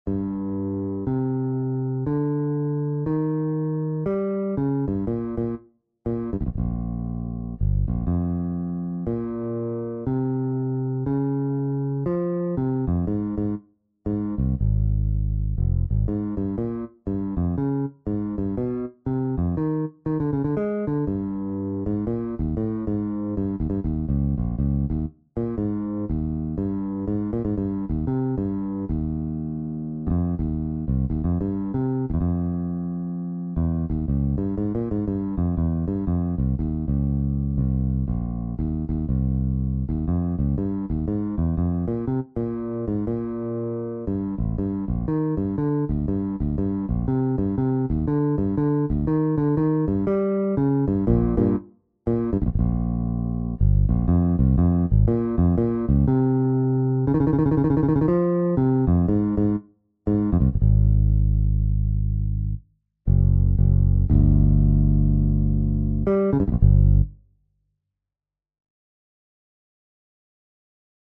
Solo Double Bass
Solo Double Bass 1:11 Jazz